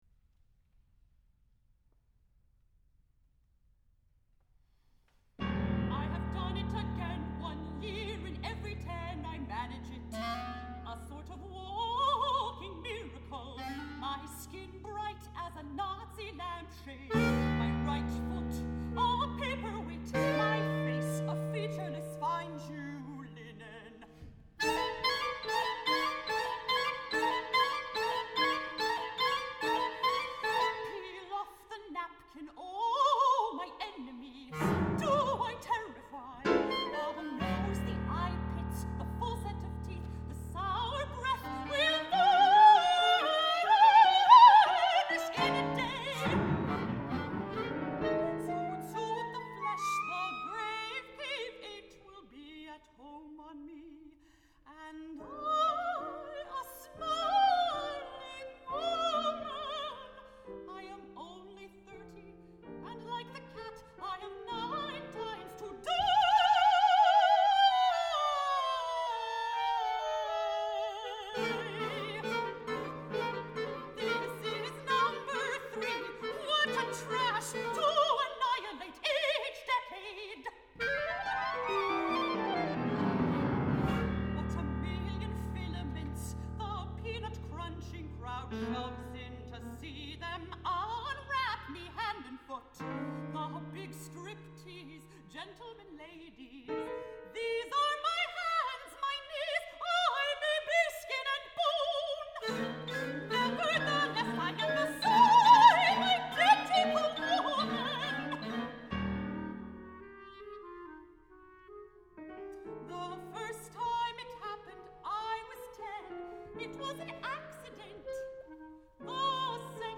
Seiji Ozawa Hall at Tanglewood